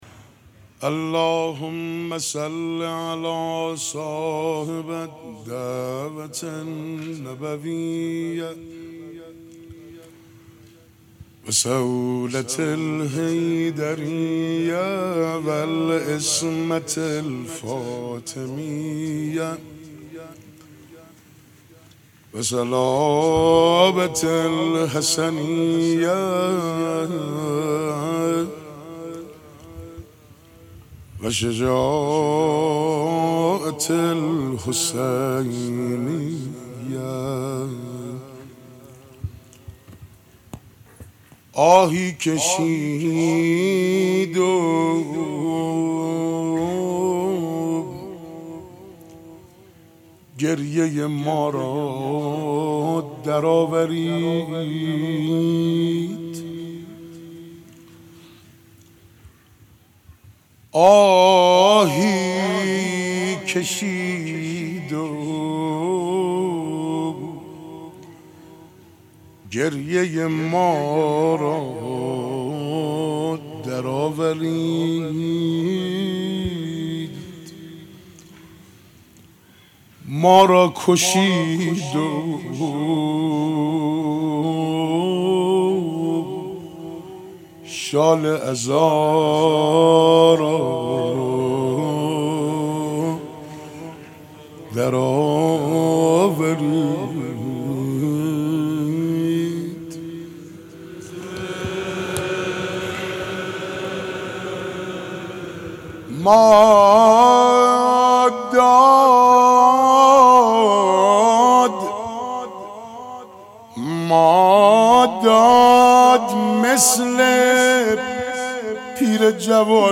مناسبت : شب اول محرم
قالب : روضه